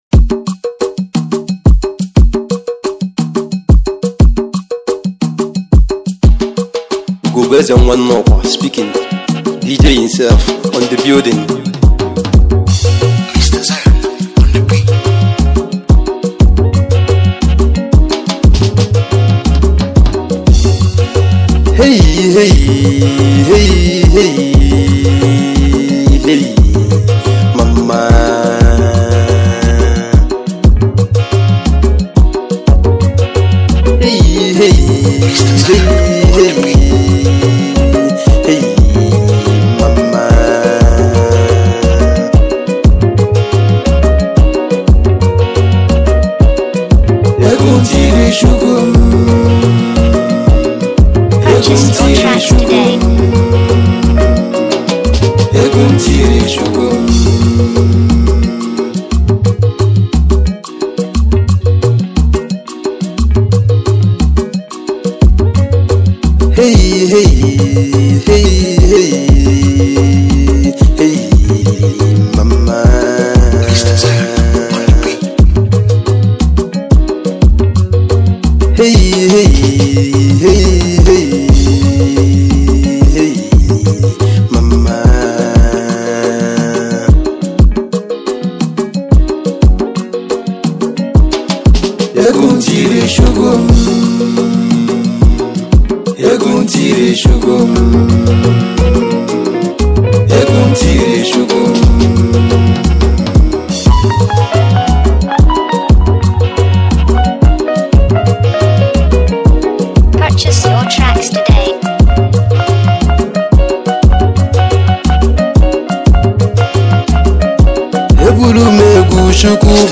highlife music band